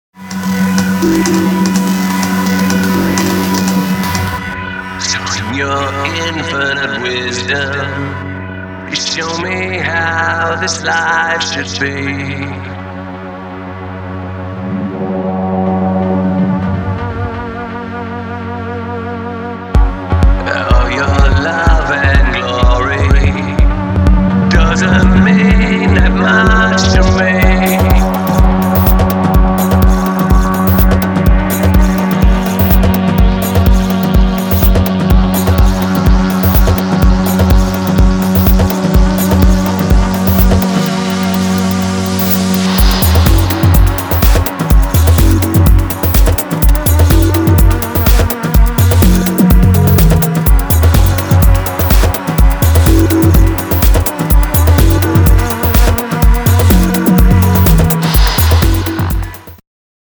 Dub Mix